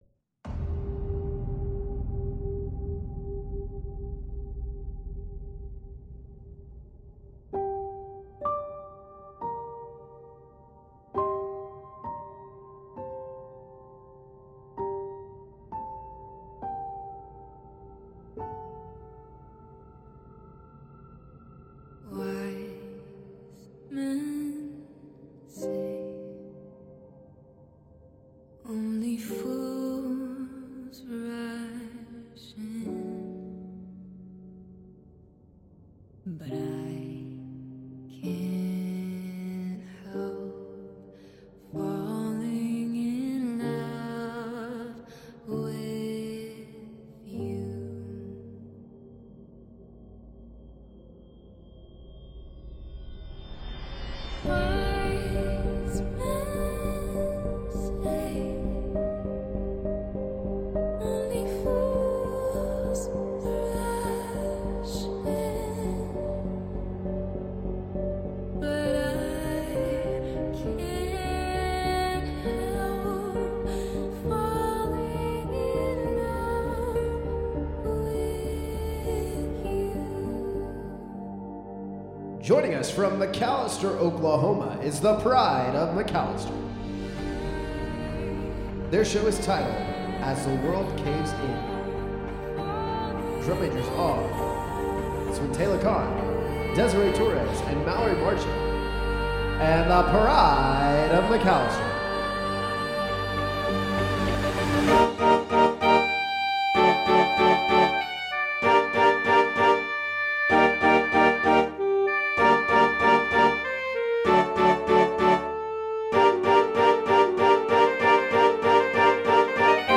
A dark concept